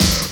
Snare Drum 67-10.wav